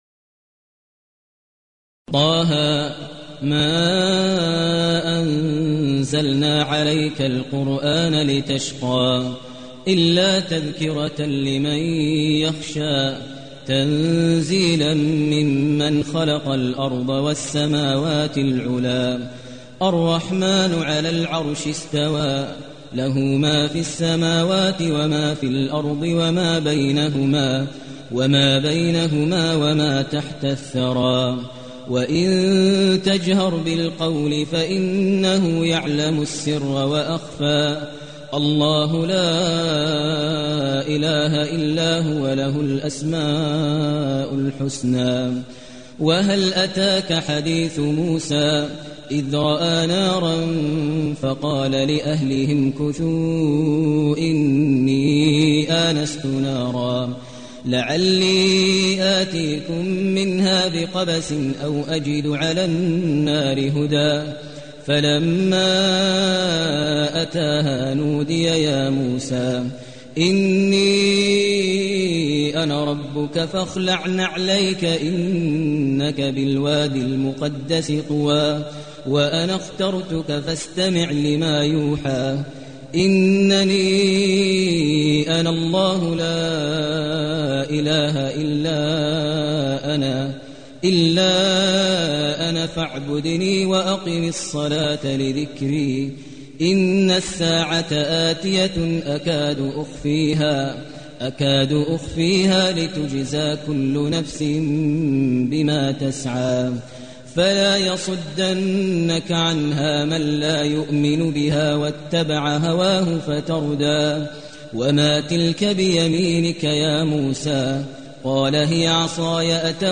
المكان: المسجد النبوي الشيخ: فضيلة الشيخ ماهر المعيقلي فضيلة الشيخ ماهر المعيقلي طه